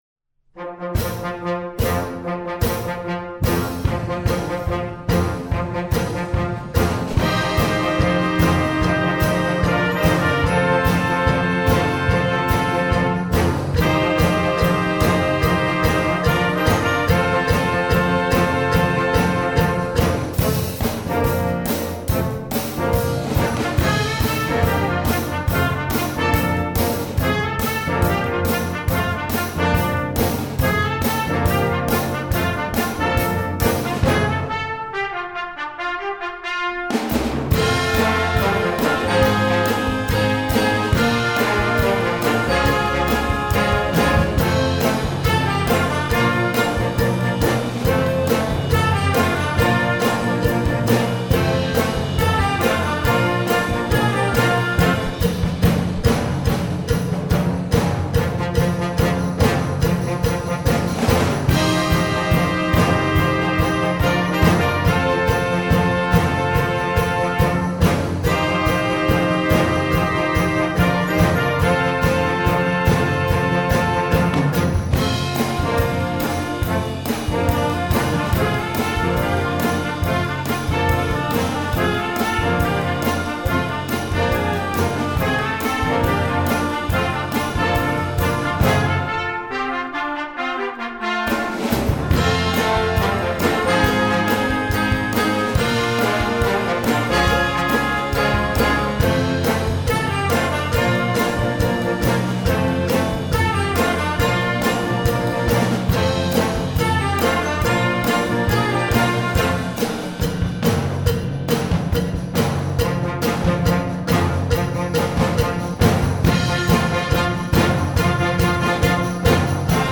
試聴サンプル